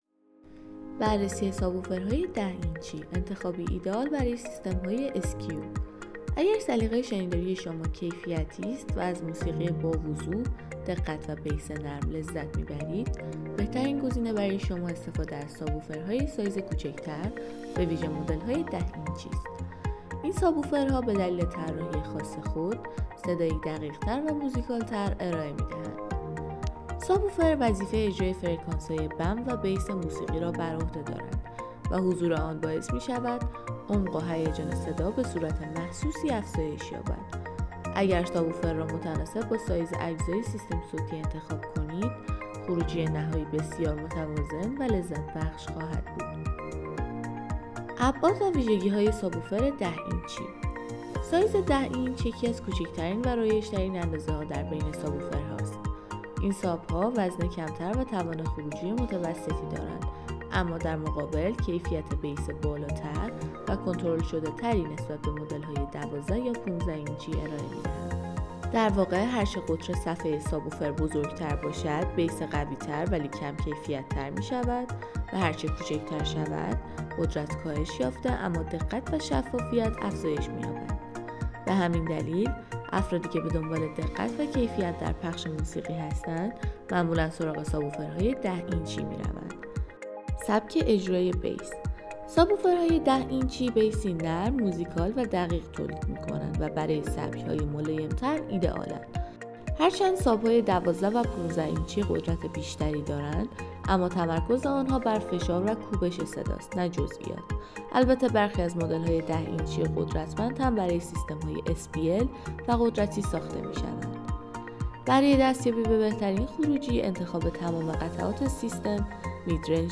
ساب ۱۰ اینچ.m4a